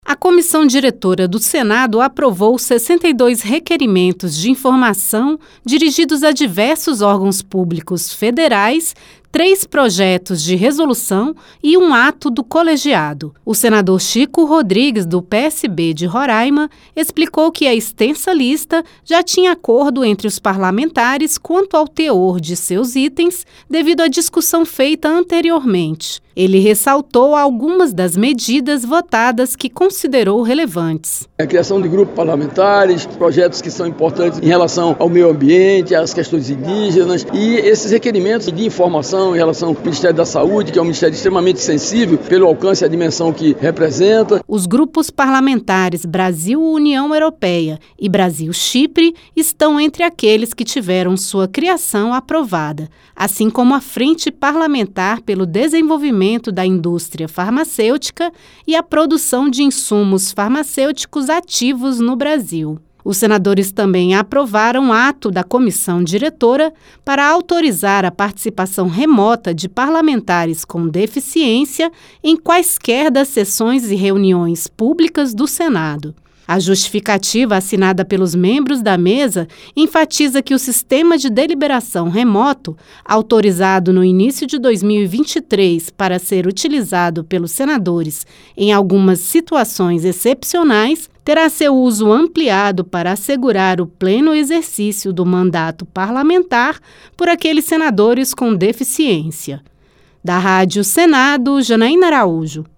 O senador Chico Rodrigues (PSB-RR), terceiro-secretário da Mesa, destacou o Ministério da Saúde como um dos principais destinatários dos pedidos de informações.